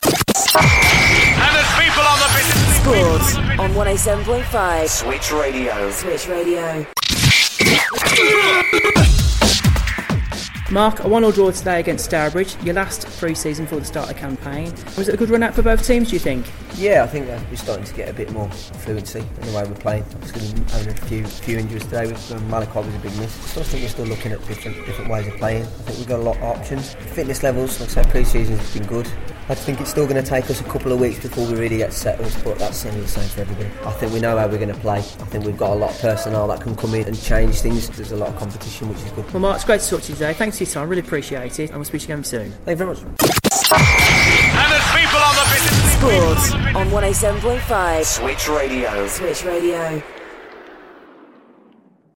Post match reaction